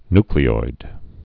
(nklē-oid, ny-)